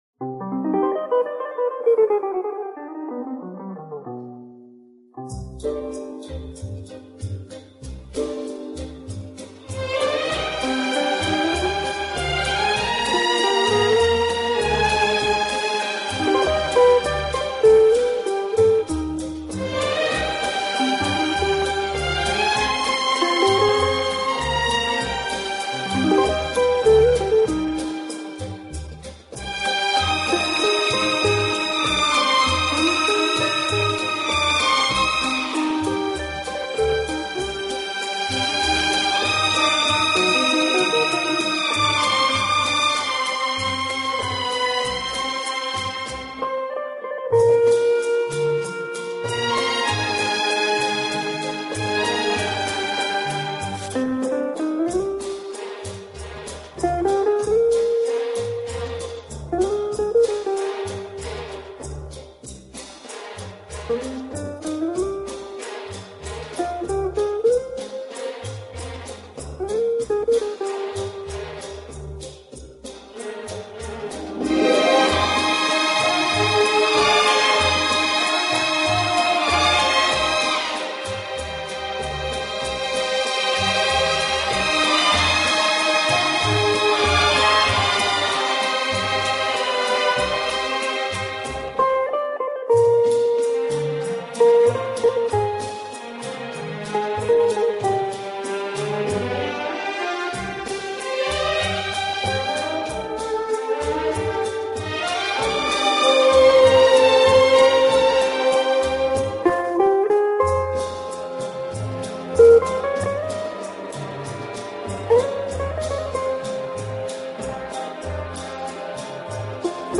【轻音乐】
轻快、节奏鲜明突出，曲目以西方流行音乐为主。